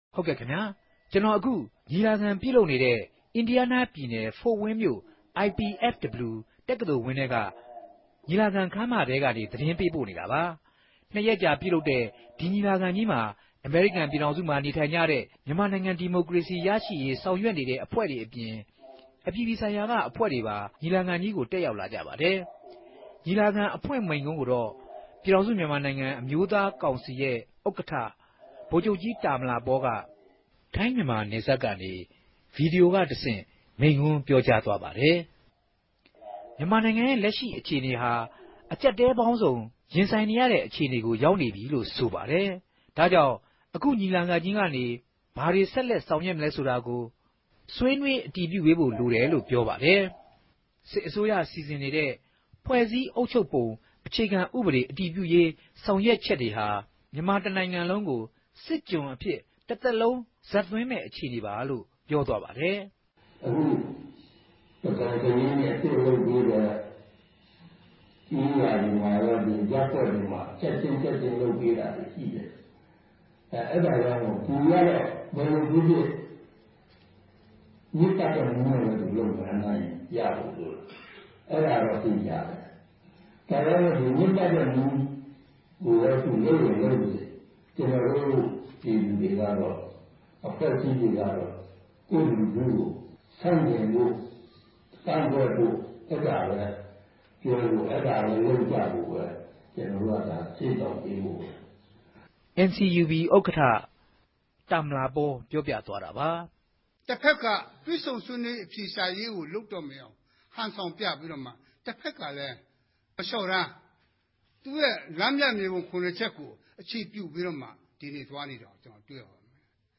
သတင်းပေးပိုႚခဵက် အူပည့်အစုံကို နားဆငိံိုင်ပၝတယ်။